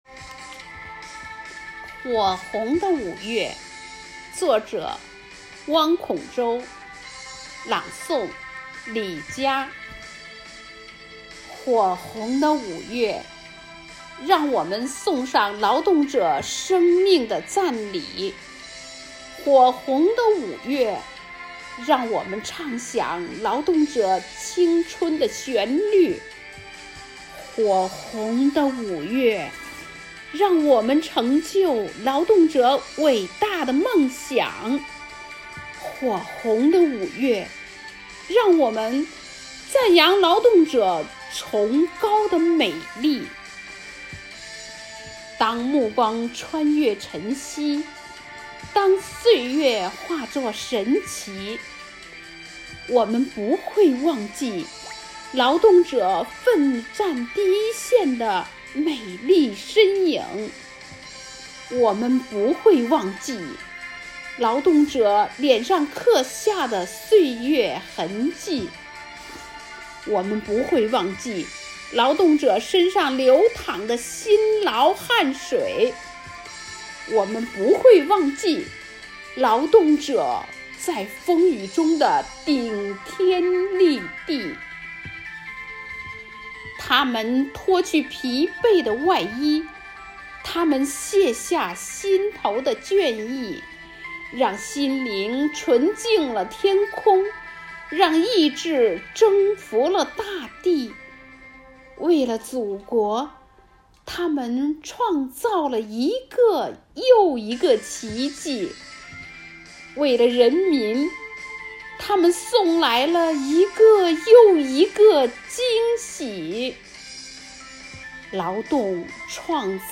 朗诵